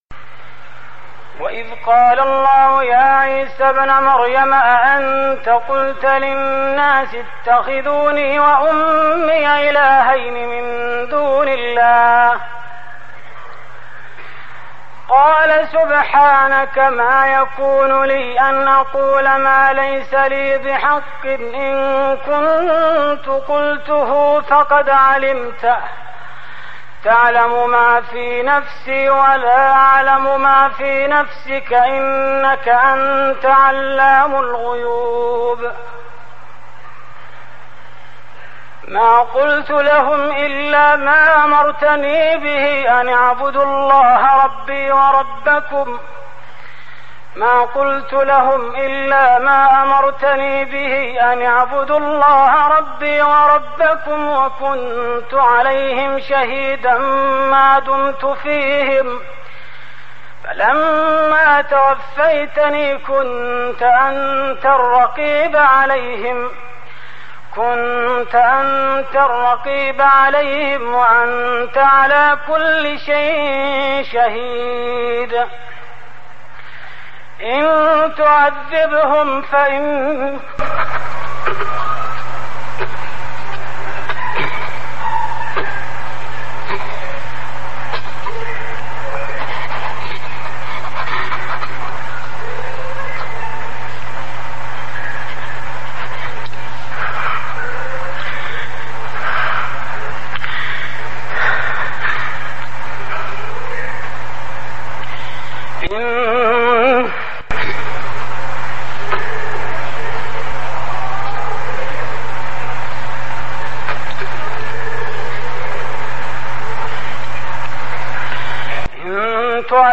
Listen online and download mp3 free beautiful recitation of Surah Al Maidah from Ayaat or Verses 116 to 120. Listen in the beautiful voice of Imam e Kaaba Sheikh Abdur Rehman As Sudais.